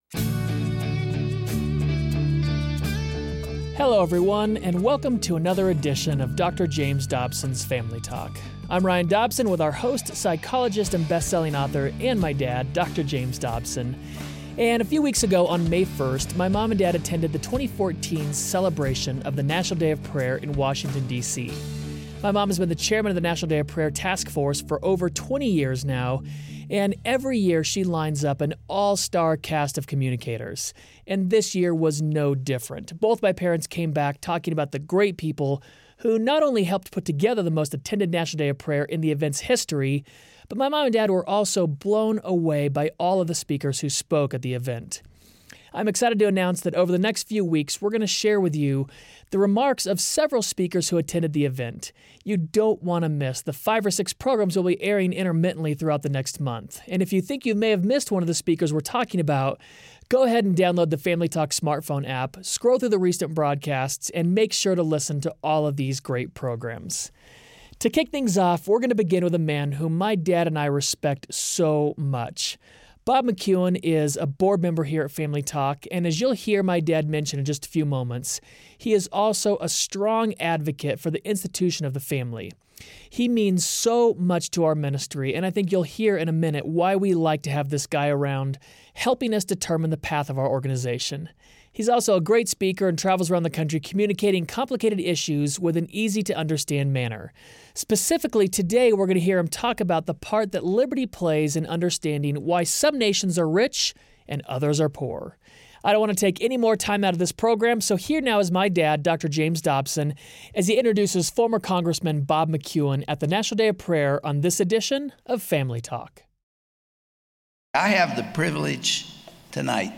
Where is the real power struggle in America? Bob McEwen reflects on Gods hand in Americas history at the 2014 National Day of Prayer.